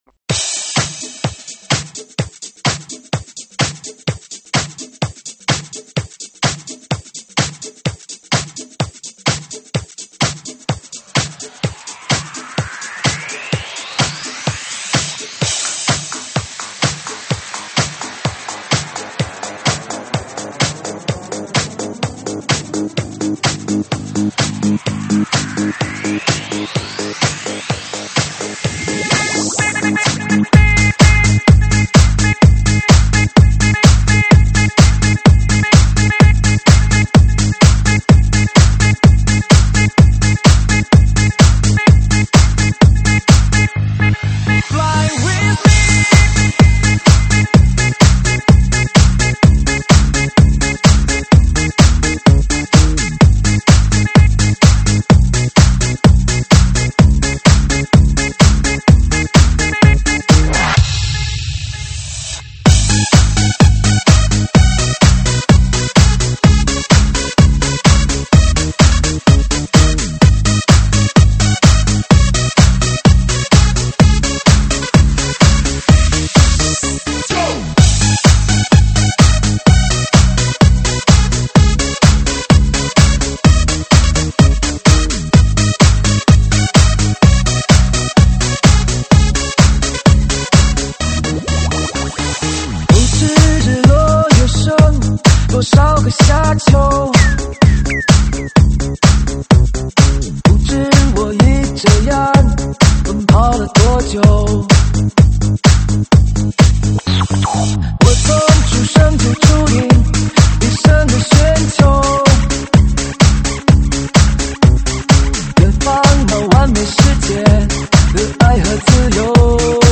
音质：320 Kbps